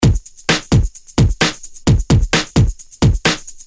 Free MP3 electro drumloops soundbank 2
Electro rythm - 130bpm 22